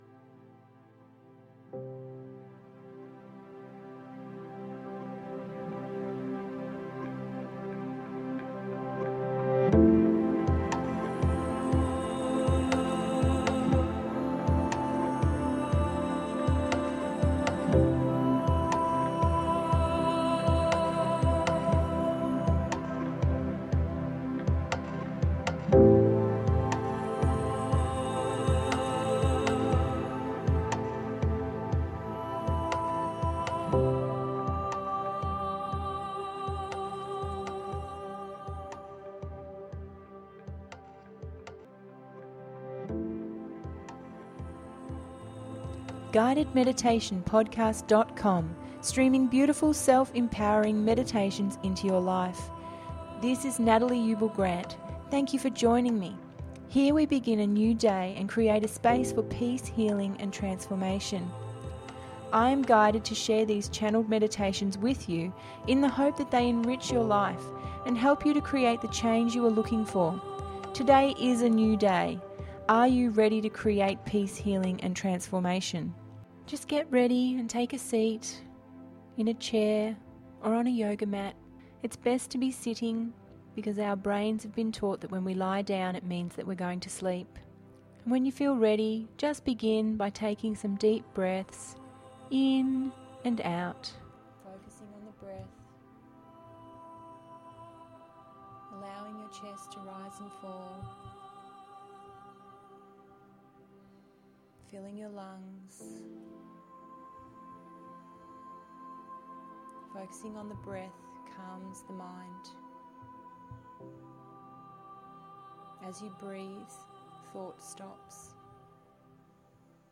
I AM – New Moon Meditation…034 – GUIDED MEDITATION PODCAST